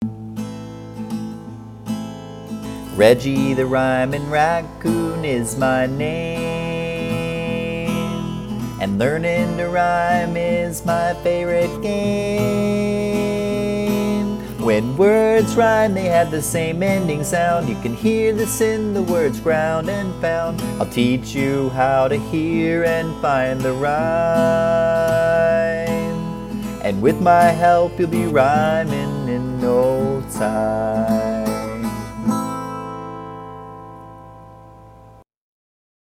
Reggie-Vocals.mp3